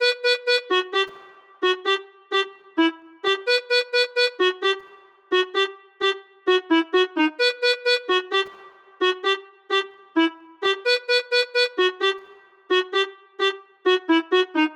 Each sample is meticulously crafted to help you achieve the same hard-hitting and soulful vibe that Divine is known for.
Gully-Loops-Mirchi-Melody-Loop-BPM-130-E-Min.wav